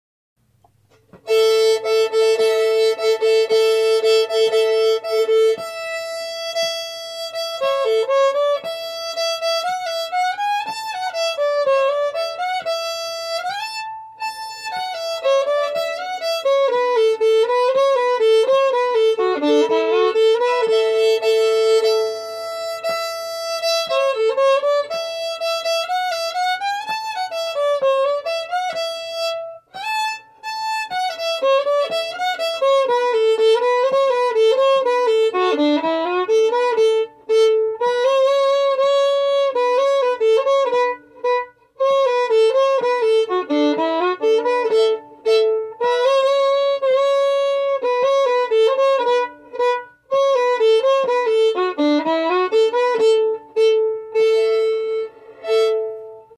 Key: A
Form: Reel
Played very slowly for learning
Source: Trad.
Genre/Style: Old time